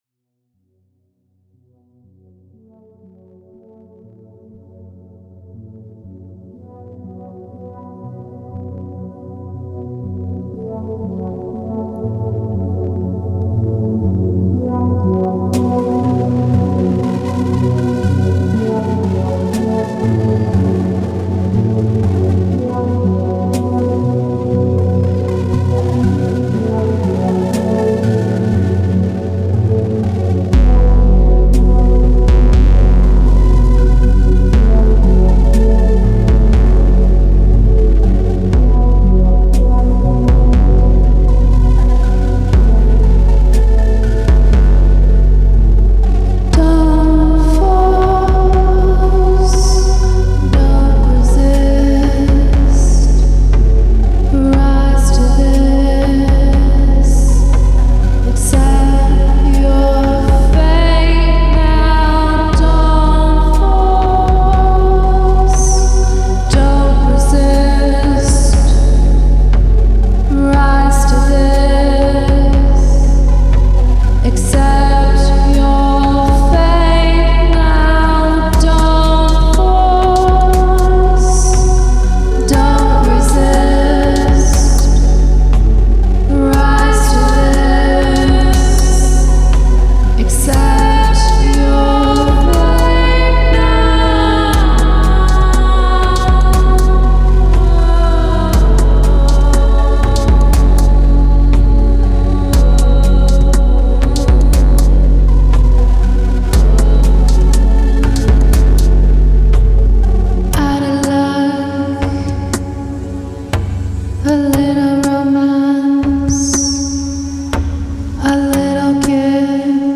De la cold wave electro assez dépouillée